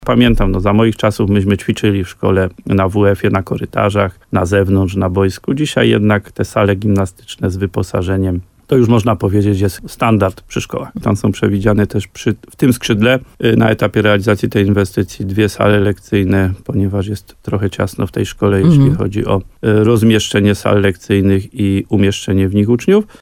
Tam są przewidziane w tym skrzydle dwie sale lekcyjne, ponieważ jest trochę ciasno w tej szkole, jeśli chodzi o sale lekcyjne i rozmieszczenie w nich uczniów – dodaje wójt gminy Łososina Dolna, Adam Wolak. Dodatkowe sale mają ułatwić organizację lekcji dla uczniów, dla których po reformie edukacji dotyczącej ośmioklasowych podstawówek brakuje miejsca.